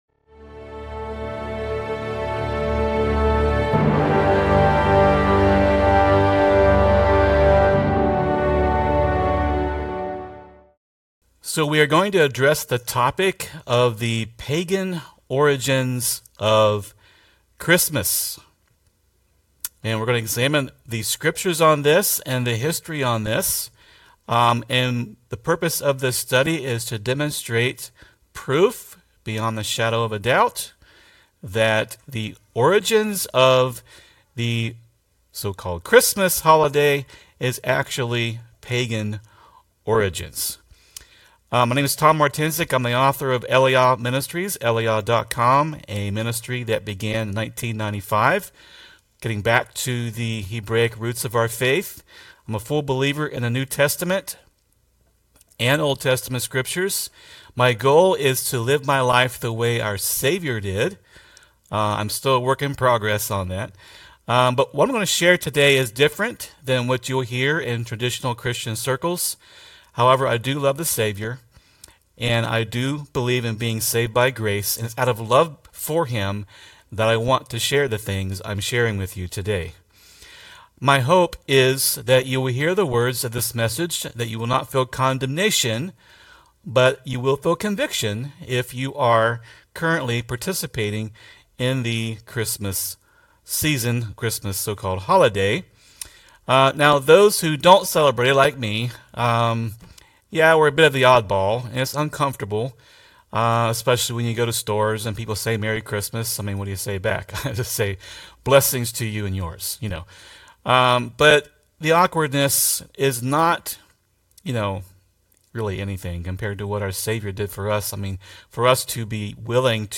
Video Transcript This is a direct transcript of a teaching that was presented via video.